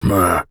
Male_Grunt_Hit_08.wav